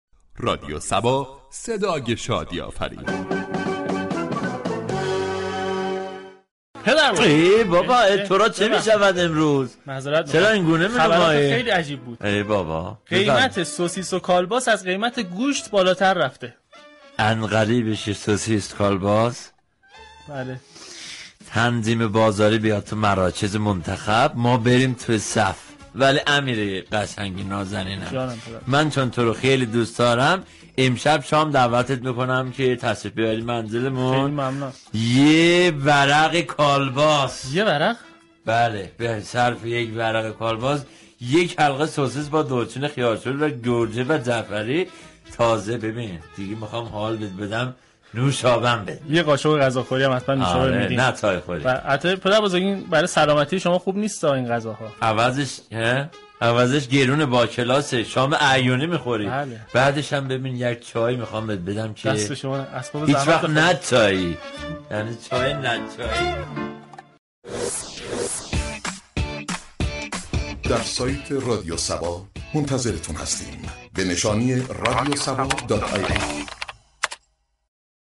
صبح صبا كه هر روز درساعت 07:00 صبح با پرداختن به موضوعات و اخبار روز جامعه ،لبخند و شادی را تقدیم مخاطبان می كند در بخش خبری با بیان طنز به خبر بالا رفتن قیمت سوسیس و كالباس پرداخت.